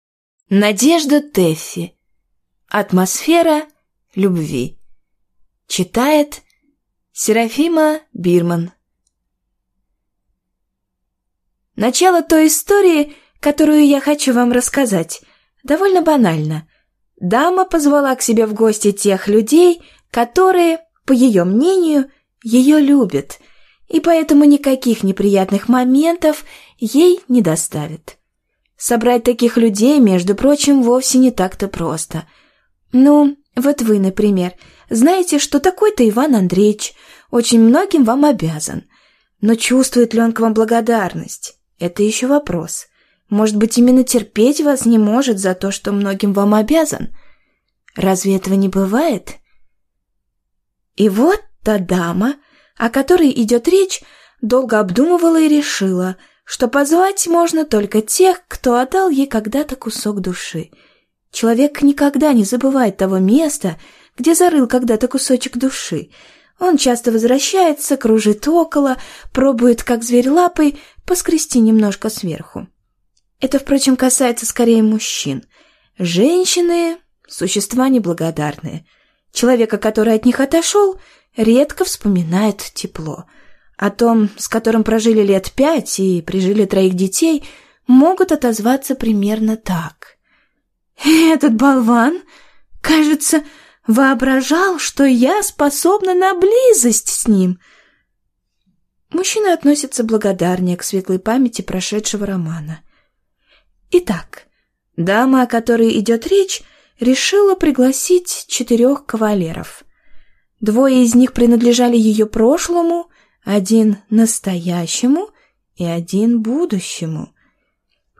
Aудиокнига Атмосфера любви Автор Надежда Тэффи Читает аудиокнигу Серафима Бирман.